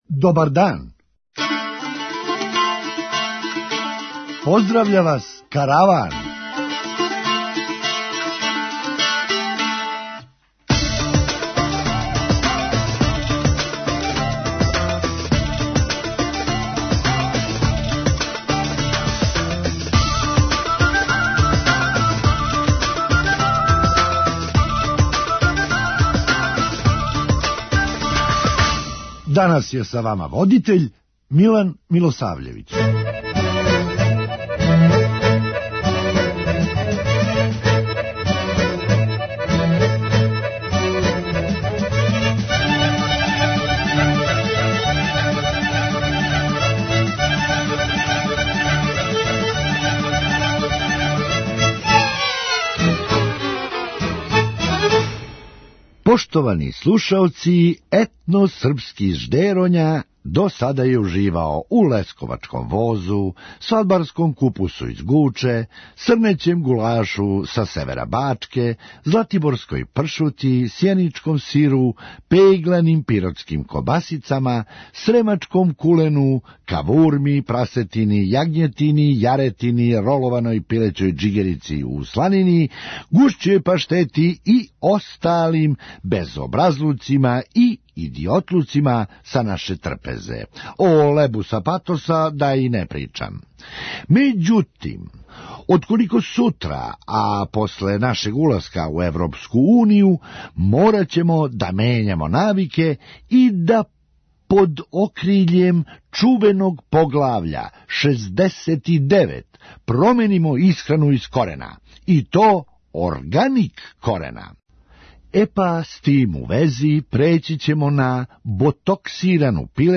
Хумористичка емисија
Да, али зато у Србији постоји и безброј вароши које су права Потемкинова села. преузми : 9.73 MB Караван Autor: Забавна редакција Радио Бeограда 1 Караван се креће ка својој дестинацији већ више од 50 година, увек добро натоварен актуелним хумором и изворним народним песмама.